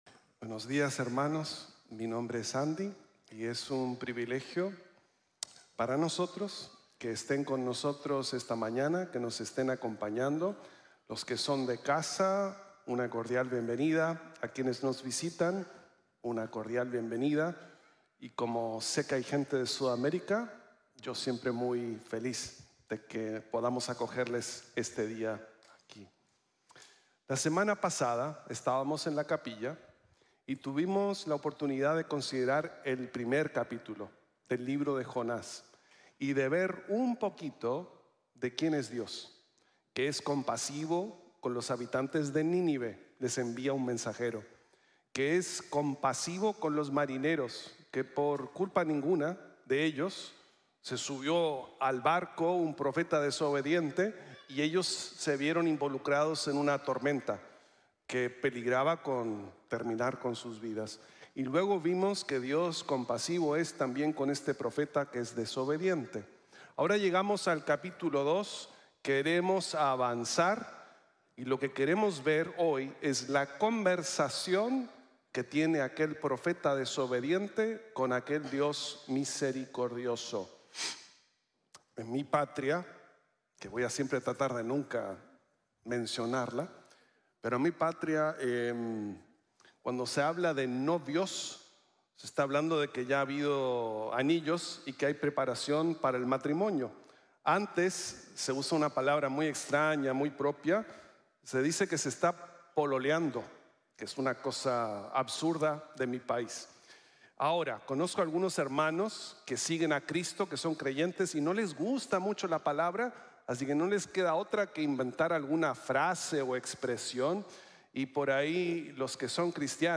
La Salvación es del Señor | Sermon | Grace Bible Church